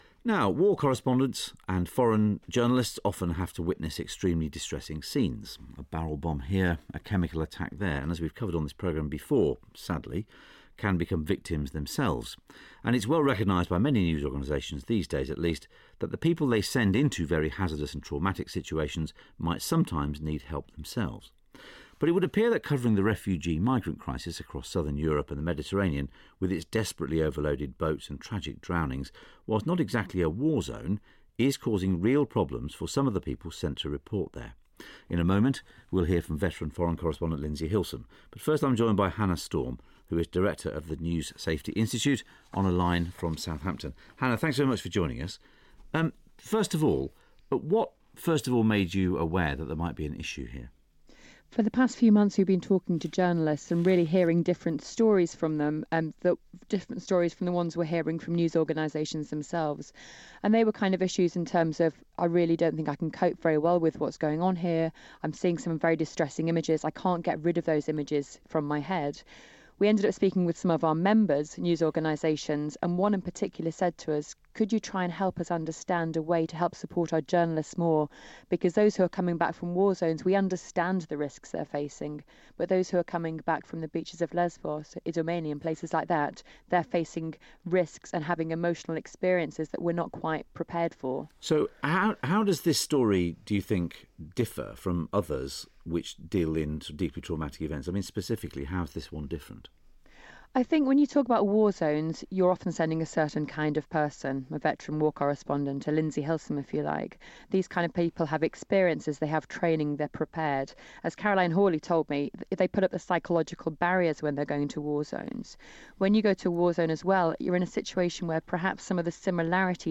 foreign correspondent Lindsey Hilsum on BBC Radio Four's The Media Show to discuss the emotional toll of reporting on refugees.